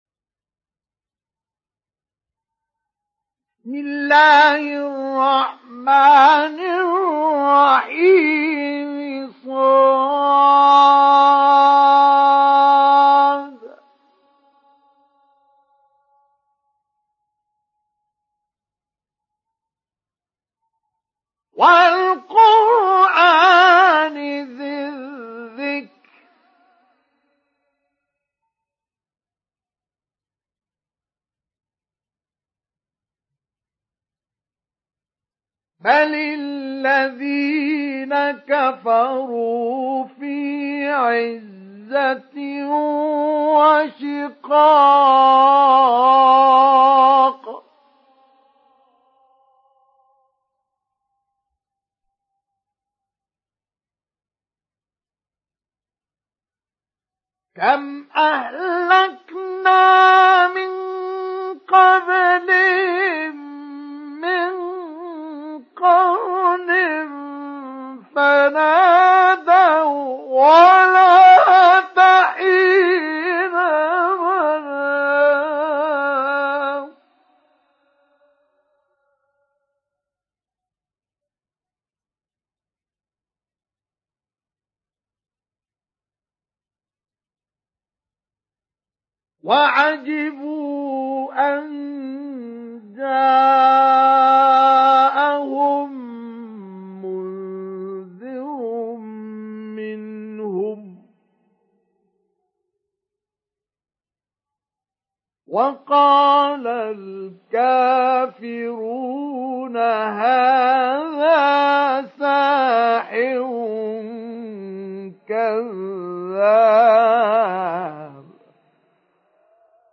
سُورَةُ ص بصوت الشيخ مصطفى اسماعيل